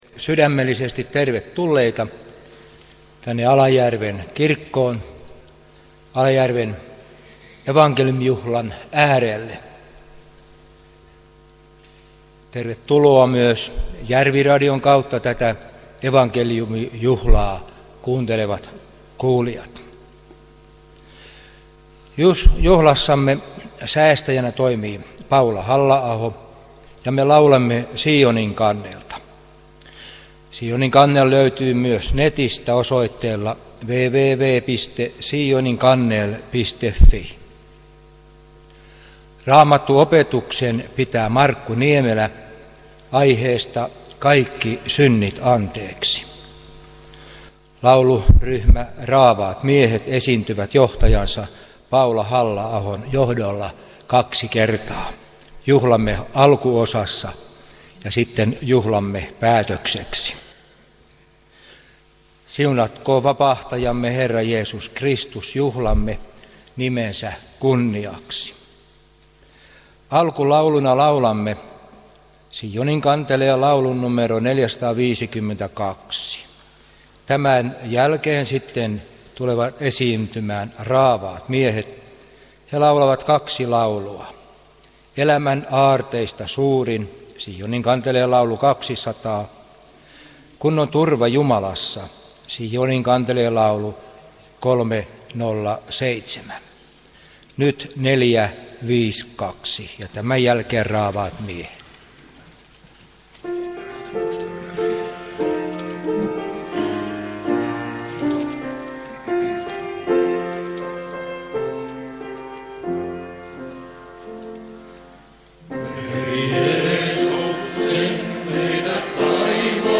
Alajärven Evankeliumijuhla 15.8.2021